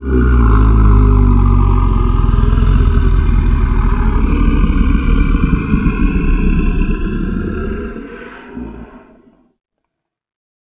creature-sound